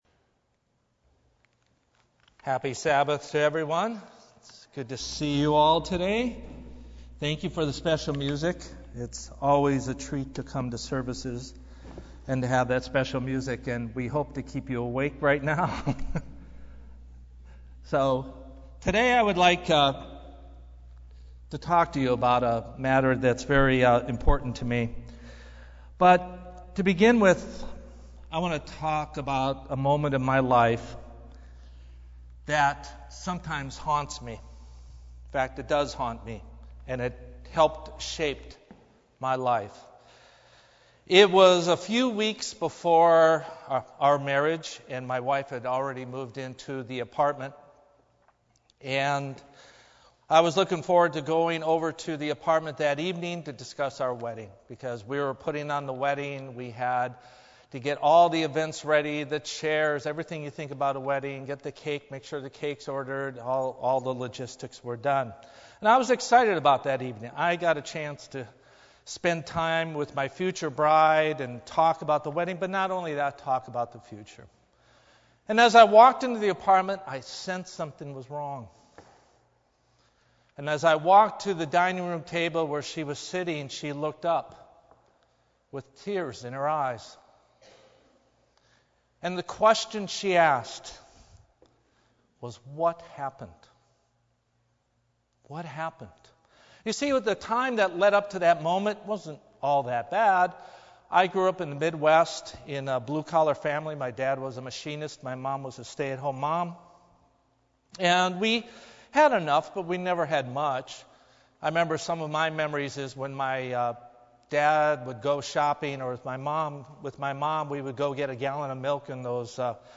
Given in Los Angeles, CA